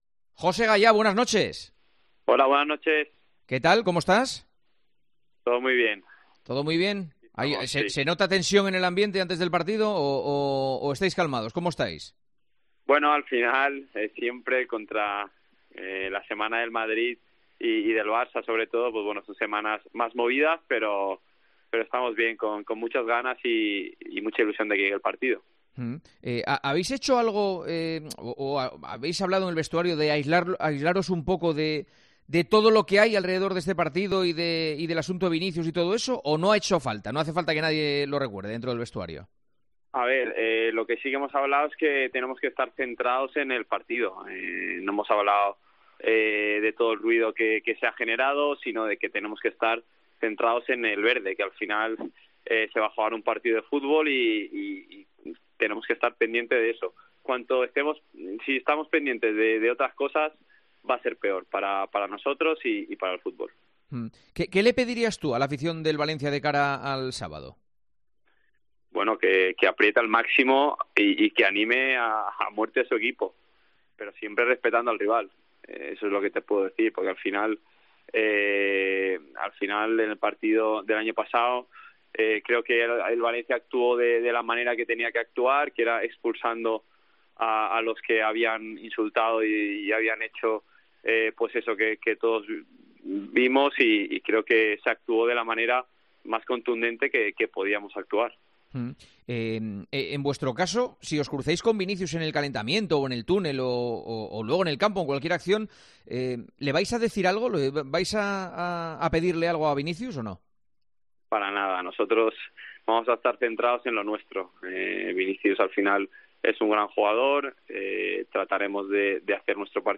El capitán del Valencia CF , José Luis Gayà , fue protagonista anoche en El Partidazo de COPE con Juanma Castaño en la previa del duelo del sábado entre el Valencia CF y el Real Madrid.